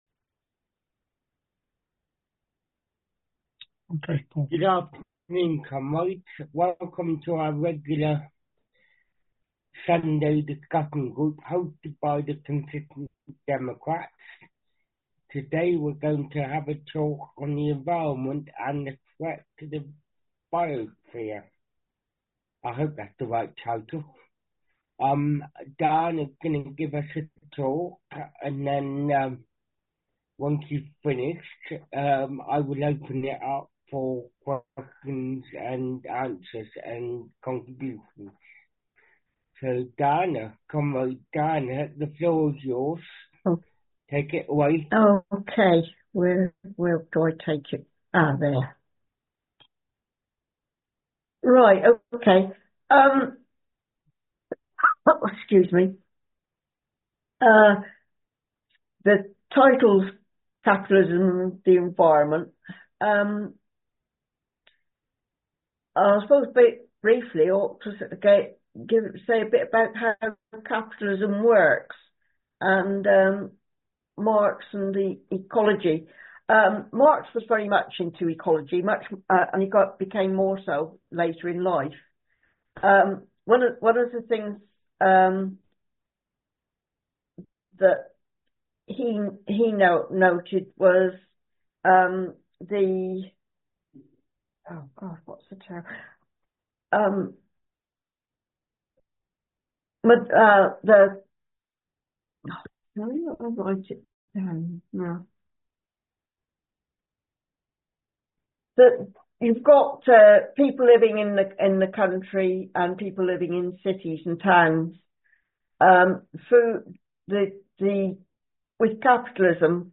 Here is a podcast of today’s discussion on capitalism and the environment. The material discussed will be the basis of a future article.